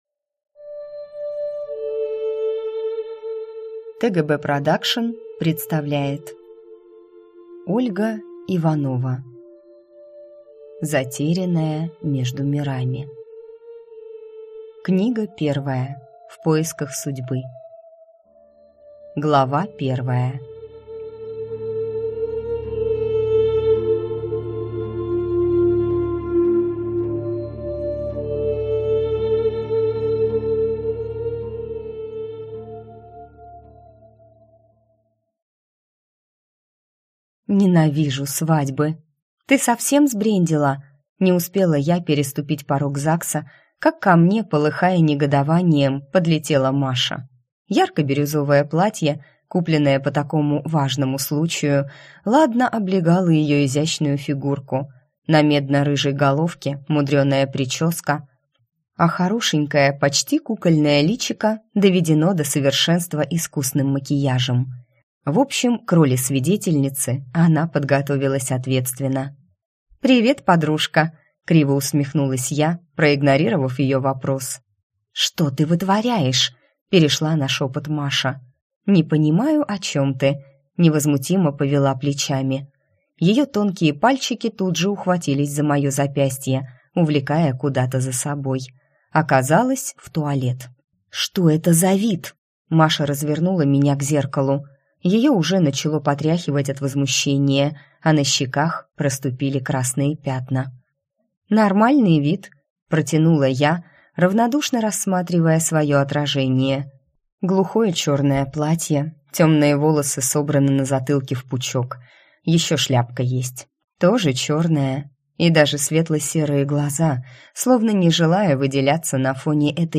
Аудиокнига Затерянная между мирами. В поисках судьбы | Библиотека аудиокниг